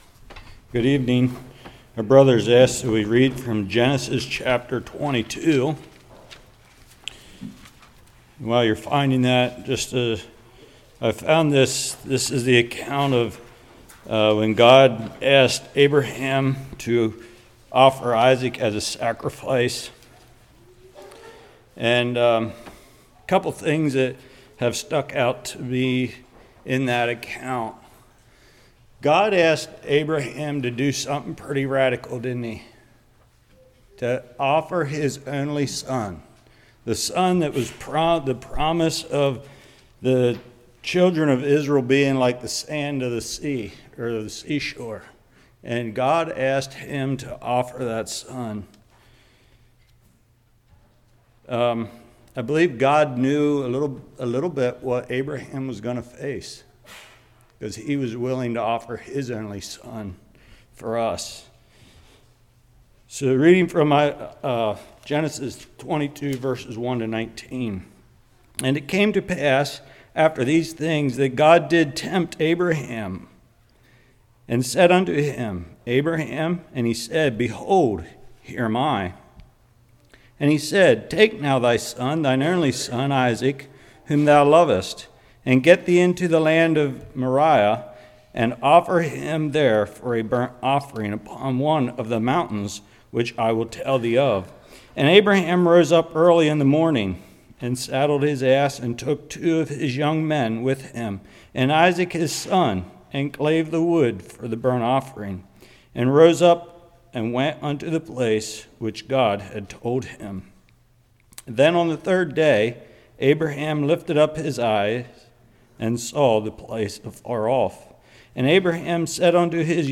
Genesis 22 Service Type: Love Feast Pre-Love Feast Sermon Lesson’s from Abraham’s Faith Recounting of the Abraham’s Life up to today’s lesson. For Faith to grow it has to be tested.